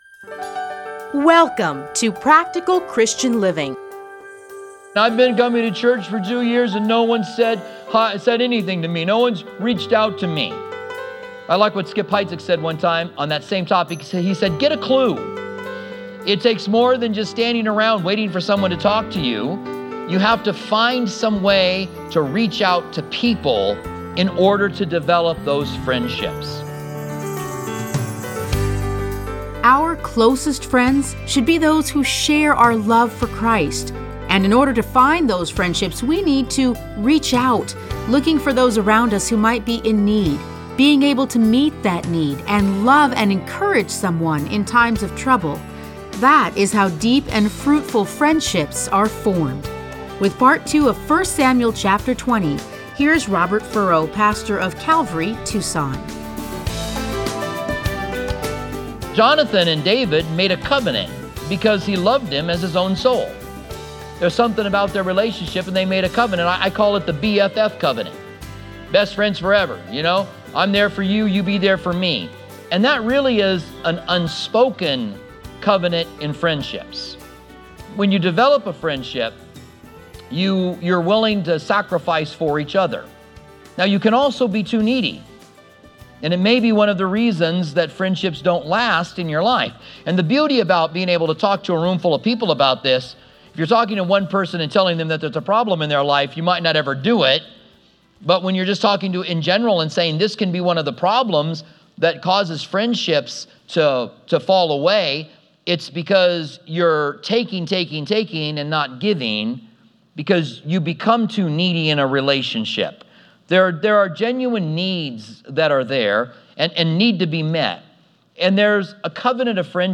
Listen to a teaching from 1 Samuel 20:1-42.